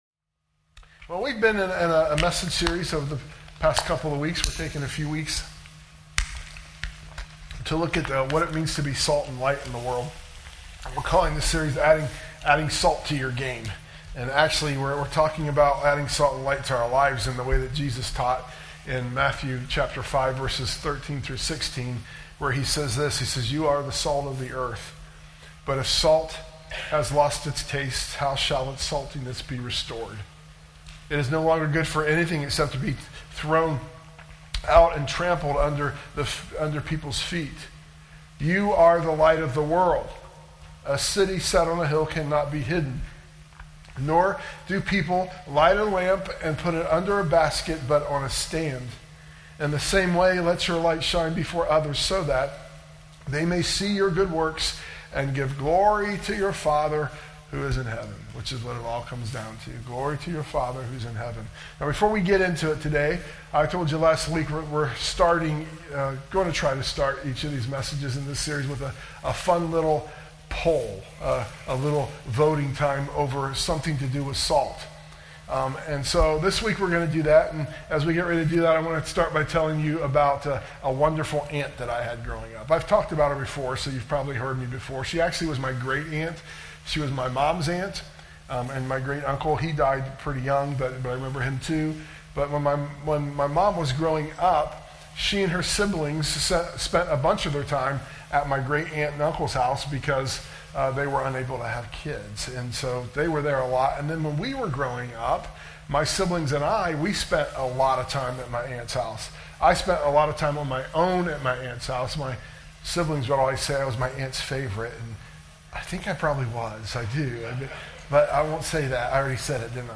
Once again, we experienced technical issues this morning.
sermon_audio_mixdown_10_5_25.mp3